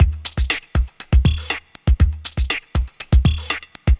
Downtempo 14.wav